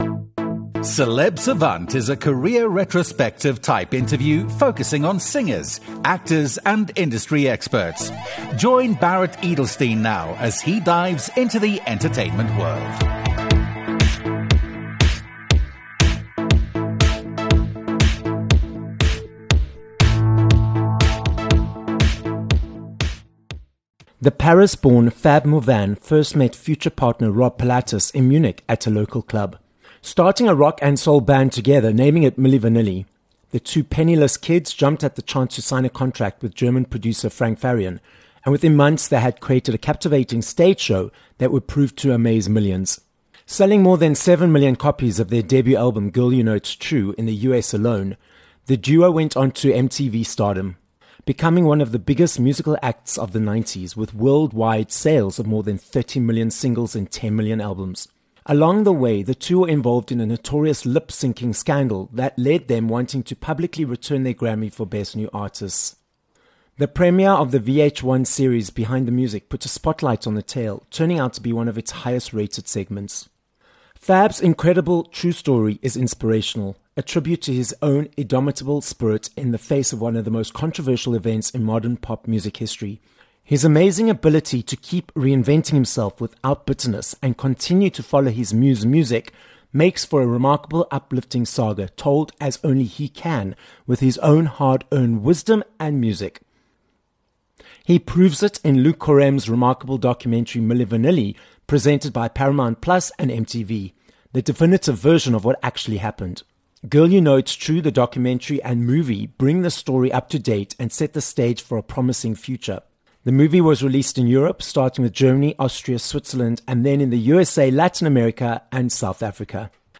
Fab Morvan - a French singer, dancer and model - joins us from Spain on this episode of Celeb Savant. Fab tells us the story of Milli Vanilli, how he pulled himself out of the dark space after the scandal to continue creating music, and the upcoming 'Turn Back The Time' festival in South Africa that Fab will be performing at.